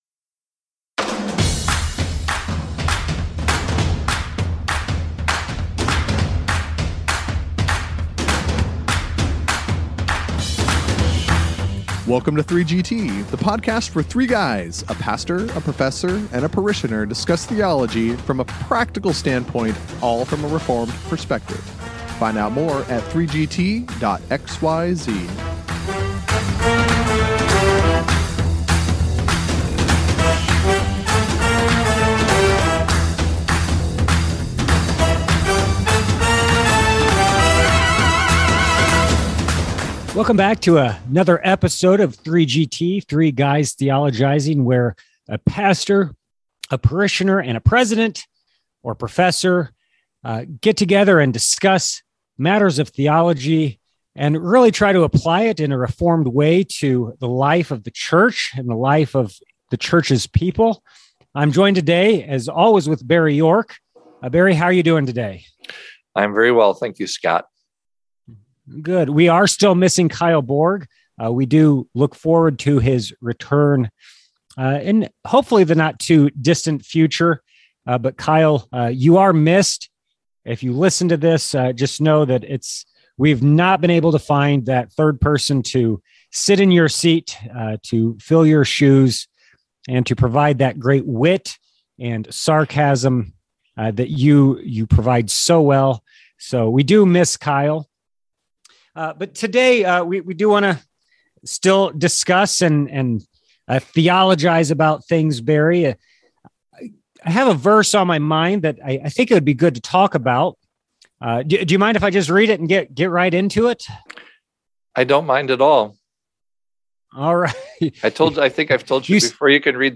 Still limping along in biunial fashion, the parishioner and professor decide to talk about the Trinity.